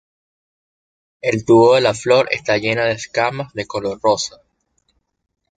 co‧lor
/koˈloɾ/